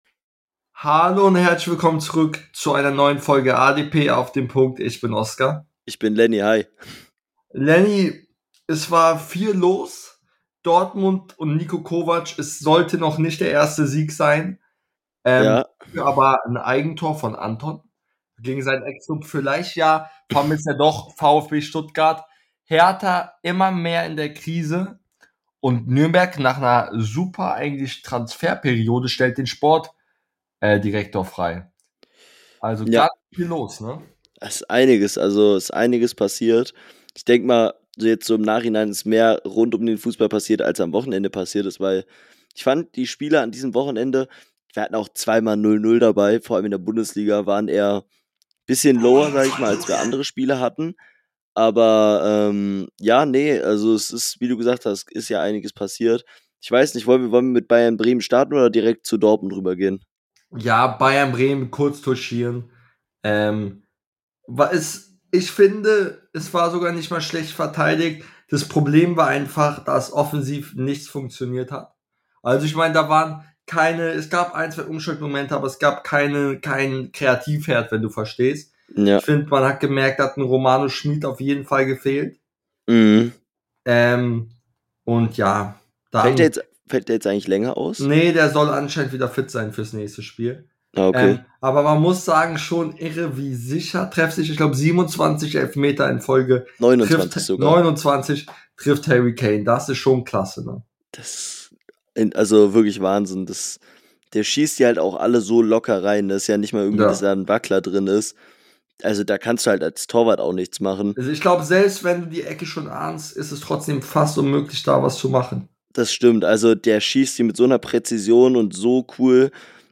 In der heutigen Folge reden die beiden Hosts über Hoffenheims Klatsche , die Endspiele für Hoffenheim und Herta nächtes Wochenende , Kovacs Premiere und vieles mehr